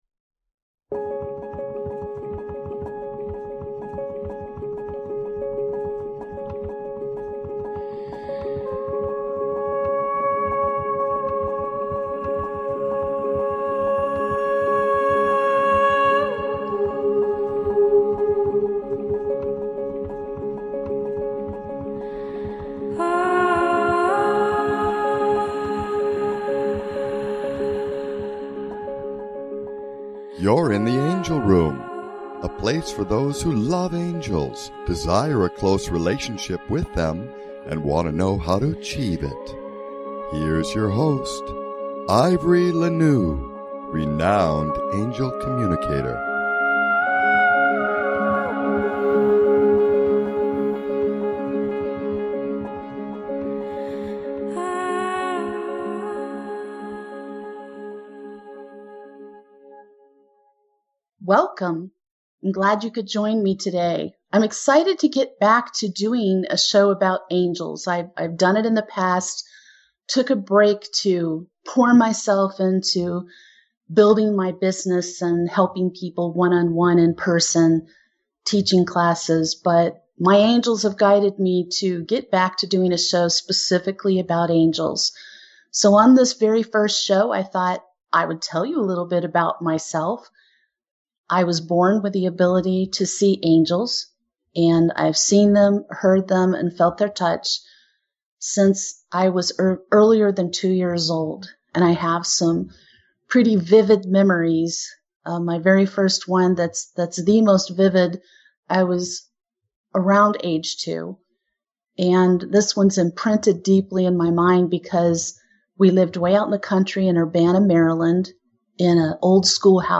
Talk Show
Spiritual topics, special guests, fascinating discussions. Call in to ask questions & receive their loving messages for you.